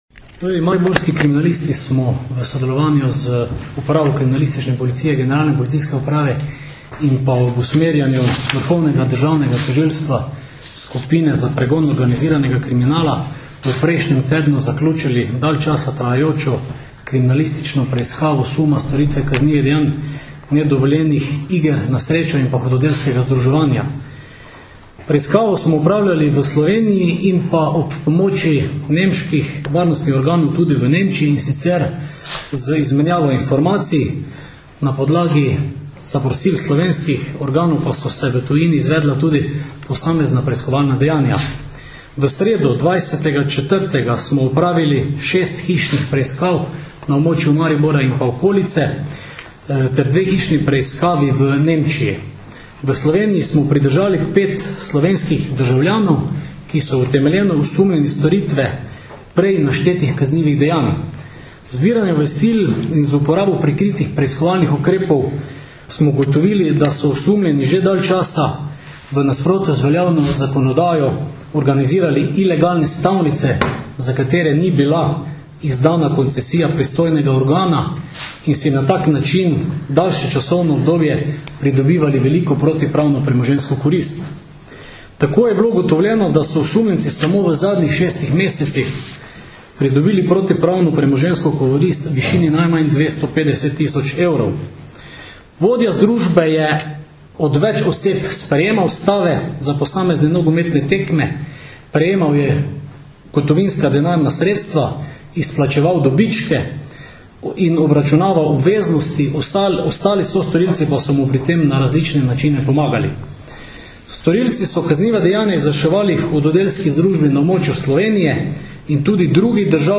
Policija - Z nedovoljenimi igrami na srečo prislužili najmanj 250.000 evrov - informacija z novinarske konference PU Maribor
Na Policijski upravi Maribor so danes, 28. aprila 2011, podrobneje predstavili preiskavo suma storitve kaznivih dejanj nedovoljenih iger na srečo.